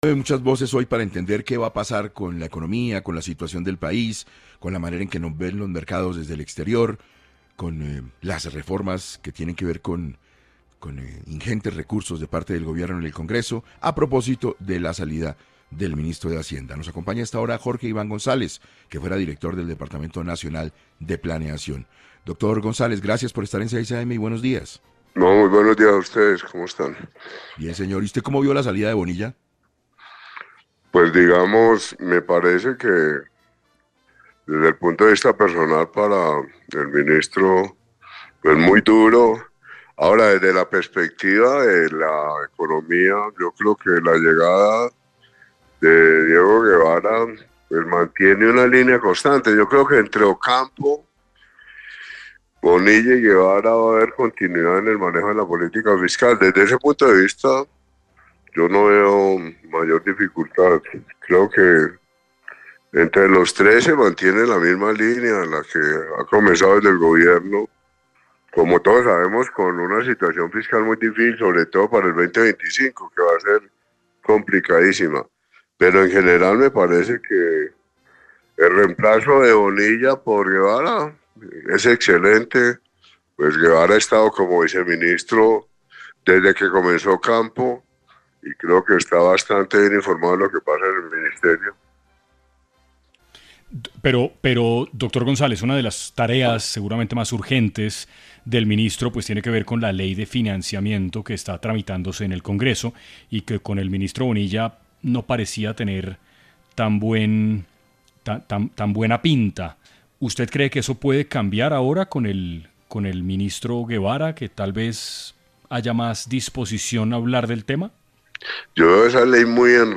Jorge Iván González, exdirector de Departamento Nacional de Planeación, se conectó en 6AM para hablar sobre el futuro del gobierno Petro tras la salida de Ricardo Bonilla y cuál será el futuro de los proyectos